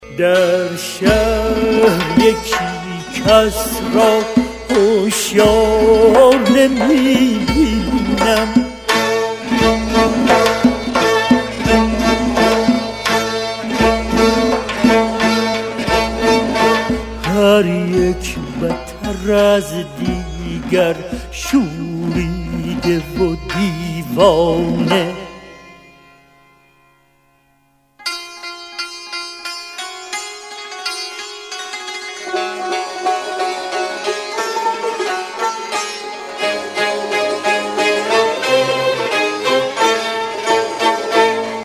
آهنگ موبایل(با کلام) سنتی و احساسی